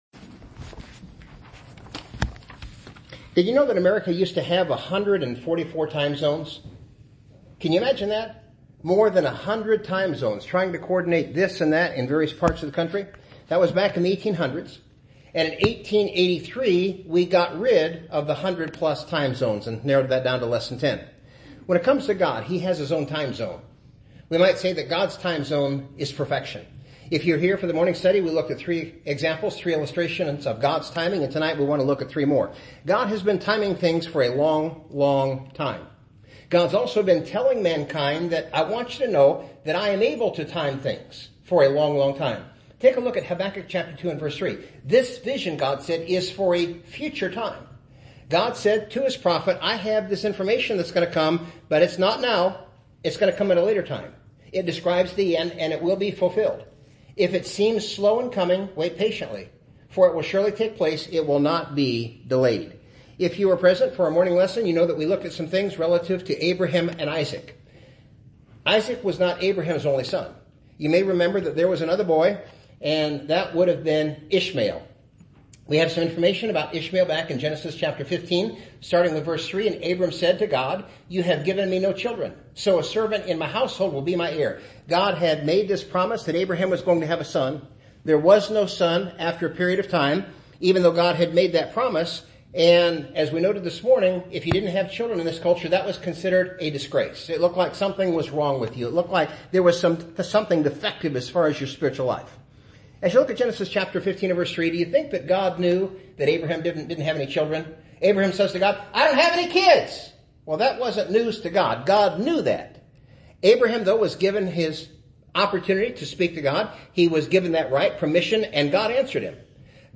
bible-study-on-gods-timing.mp3